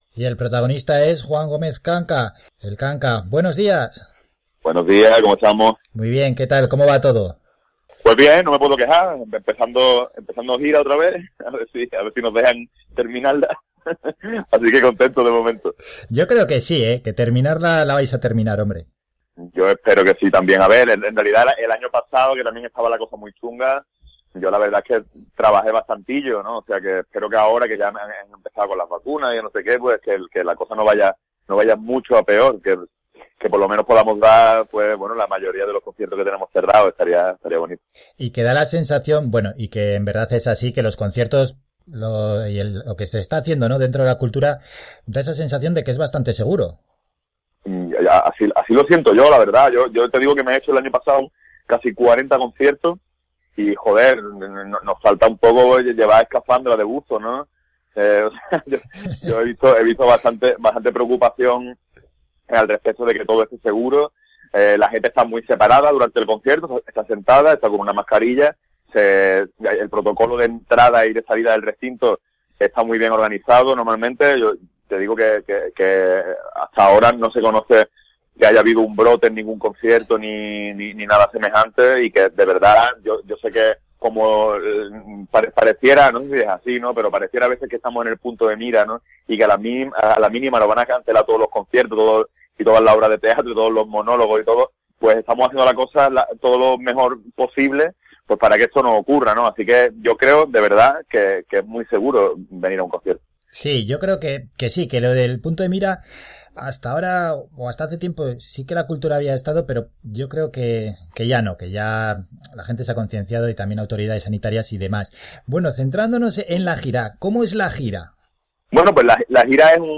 Entrevista con El Kanka – El incógnito de la semana
Hoy, jueves, 25 de marzo nos acompañó en el programa Las Mañanas de Faycan El Kanka. Con el artista malagueño charlamos para conocer cómo vive el momento actual y cómo prepara su cita del 11 de abril en el Alfredo Kraus de Las Palmas.